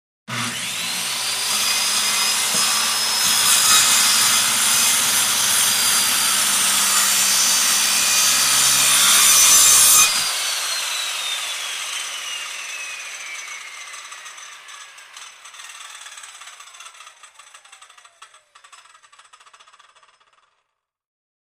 in_circularsaw_cut_02_hpx
Circular saw cuts wood as blade spins. Tools, Hand Wood, Sawing Saw, Circular